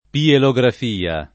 pielografia [ pielo g raf & a ] s. f. (med.)